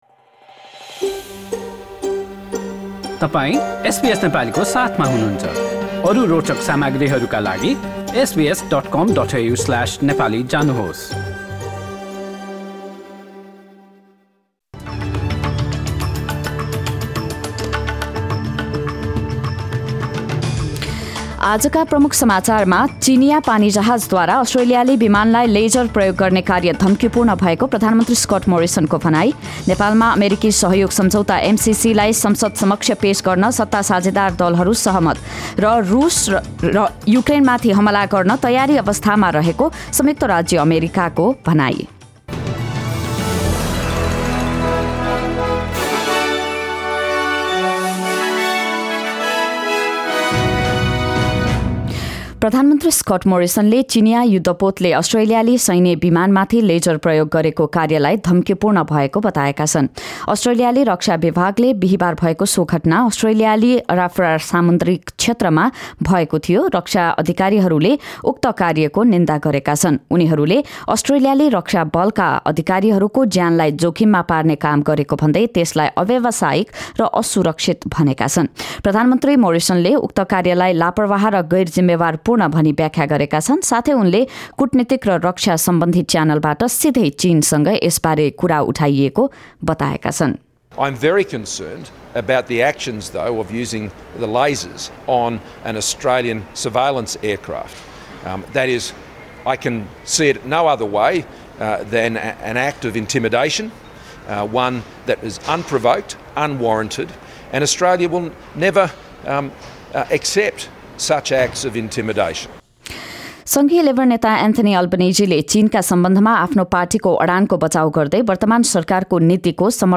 अस्ट्रेलिया समाचार: आइतबार २० फेब्रुअरी २०२२